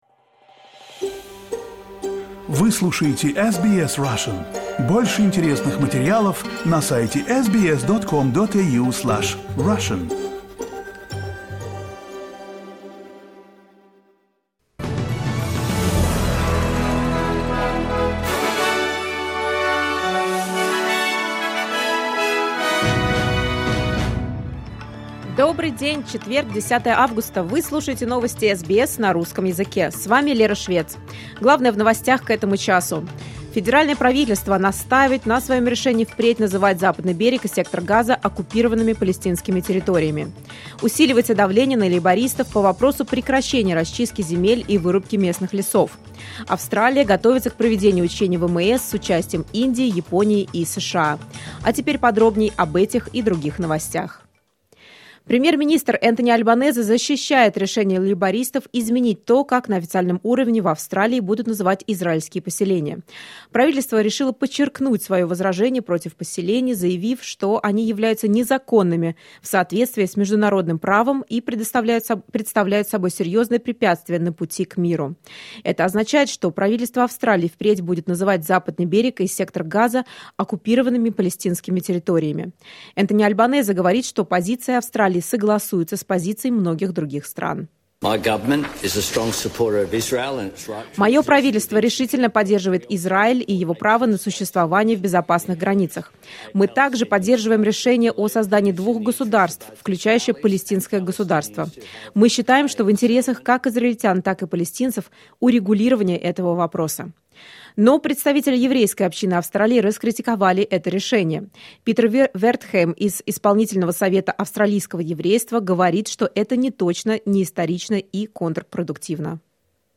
SBS news in Russian — 10.08.2023